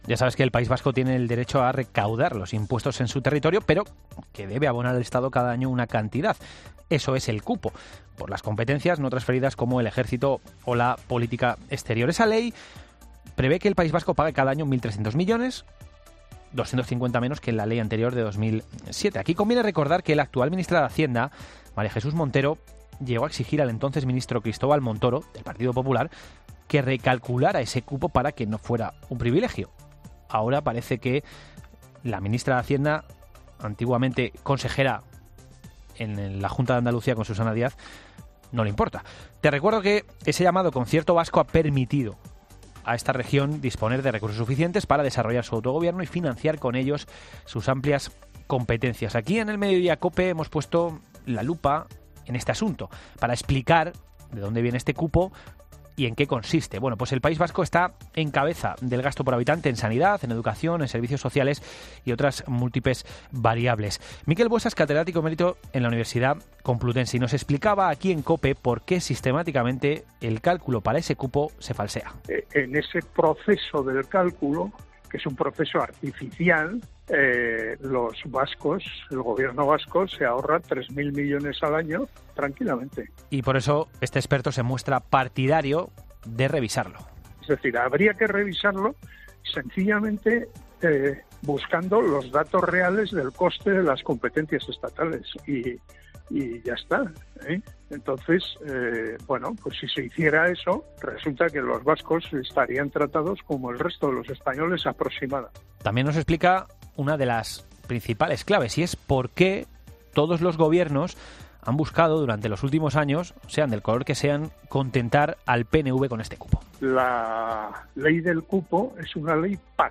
Un catedrático, sobre el cupo vasco: "Conviene buscar el coste real de las competencias estatales"